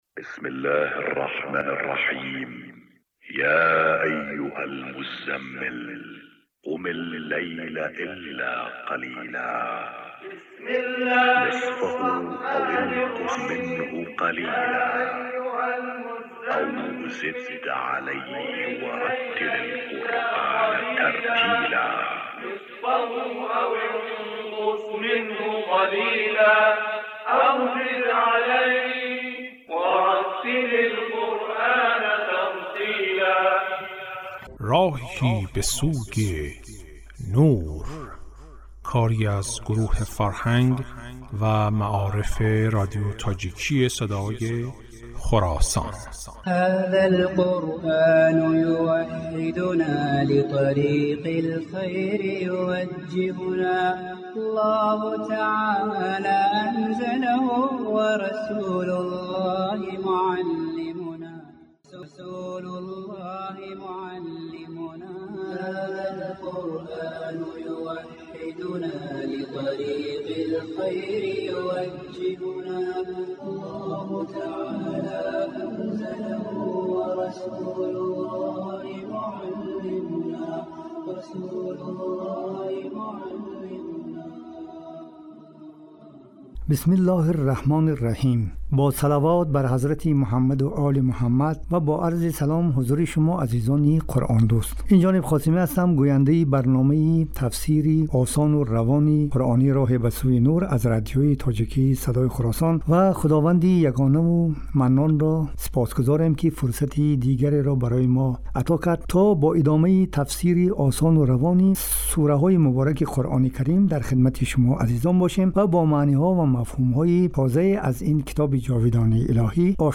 Ибтидо ба тиловати ояи 29 аз сураи “Аҳқоф” гӯш фаро мениҳем: